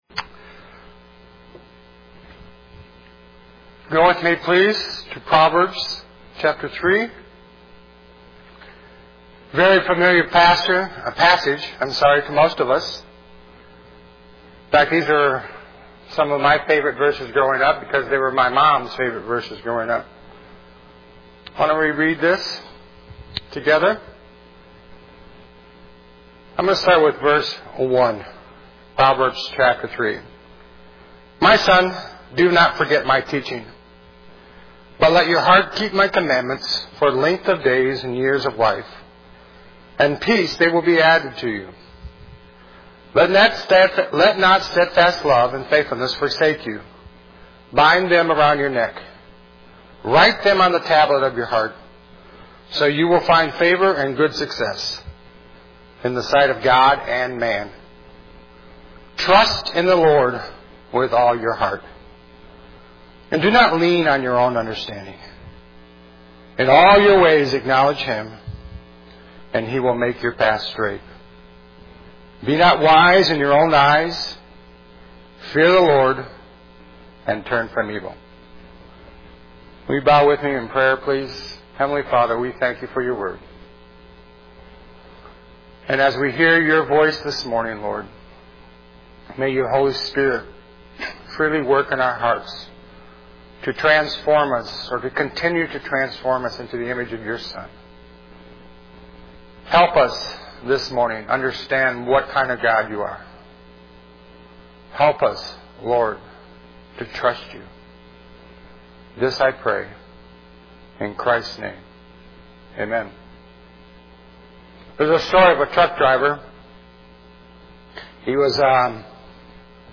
june_15__sermon.mp3